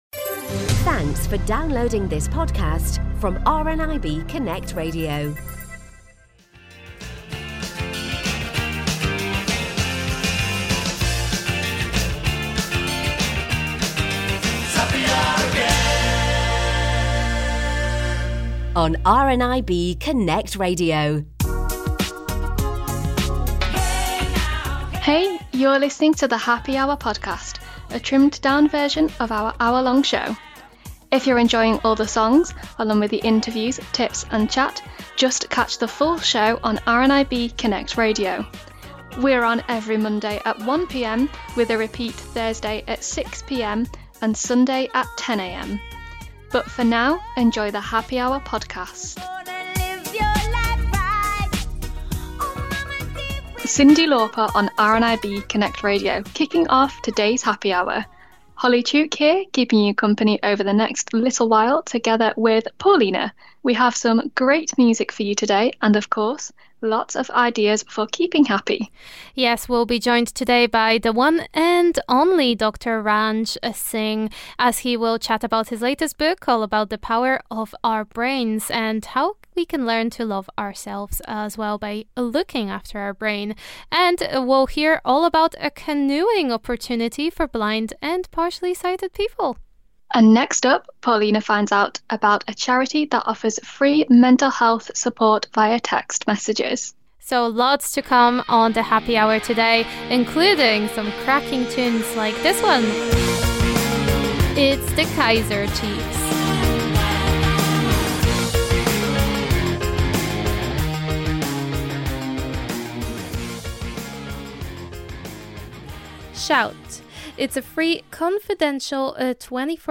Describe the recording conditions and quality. The Happy Hour Podcast is our trimmed down version of this hour-long show, so if you'd like to listen to the full show with all the amazing songs featured, catch a new episode of the Happy Hour on RNIB Connect Radio Mondays at 1 PM, with a repeat Thursday at 6 PM and Sunday at 10 am.